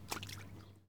tbd-station-14/Resources/Audio/Effects/Footsteps/blood3.ogg at d1661c1bf7f75c2a0759c08ed6b901b7b6f3388c
Adjust walking sounds